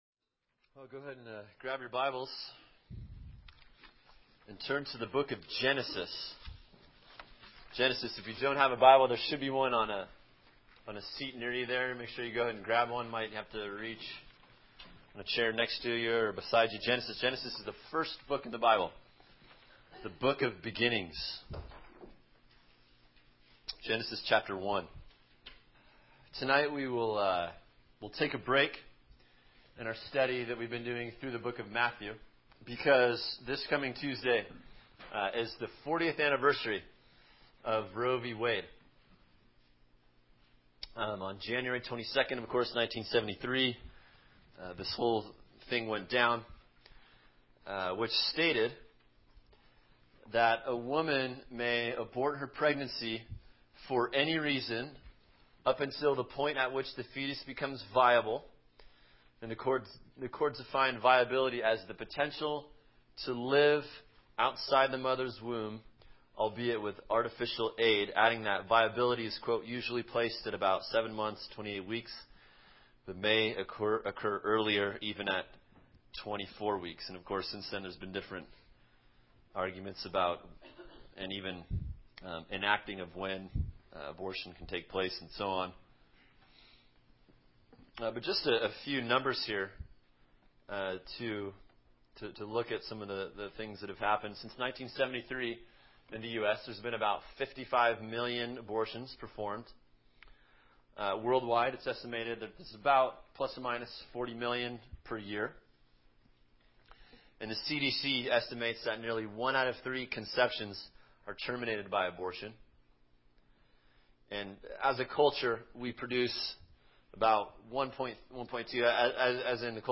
[sermon] Genesis 1:26-27 “Made In His Image” (Roe v. Wade 40th Anniversary) | Cornerstone Church - Jackson Hole